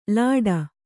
♪ lāḍa